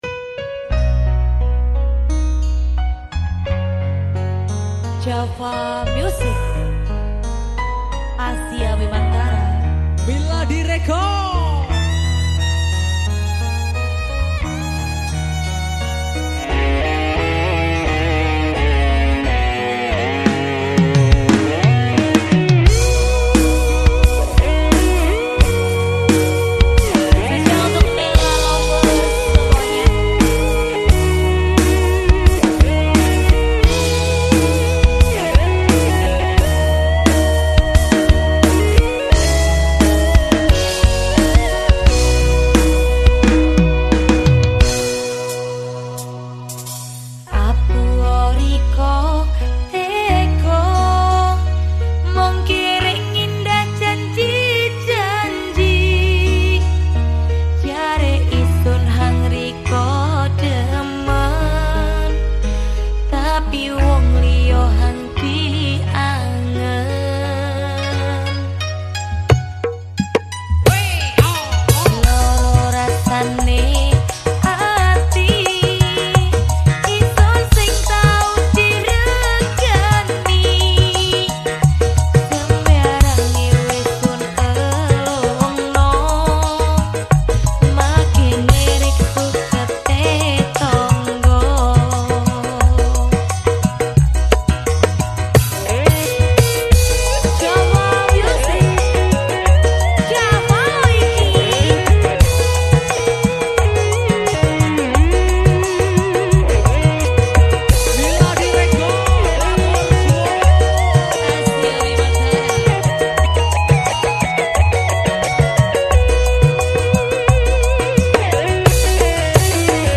dangdut koplo